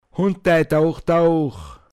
pinzgauer mundart
Hundai daoch daoch Lockruf für Hunde